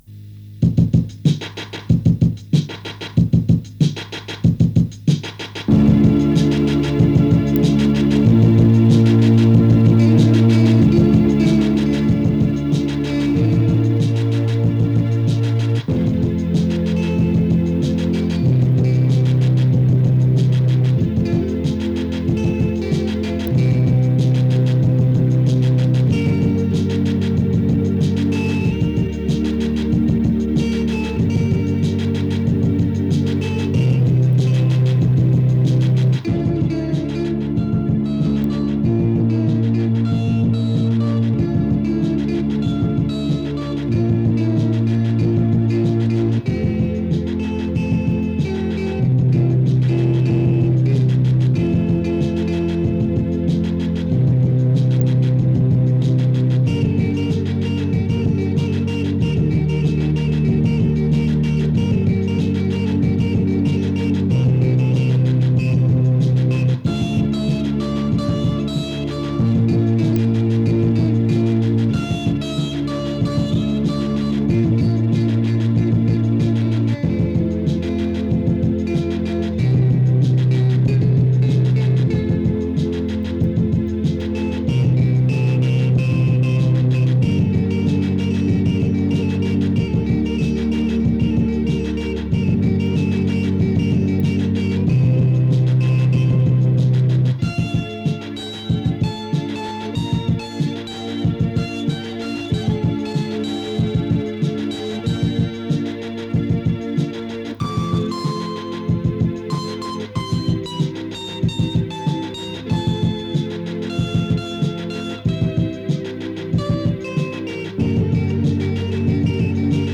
une petite pop roadsong
enregistrement original de 1984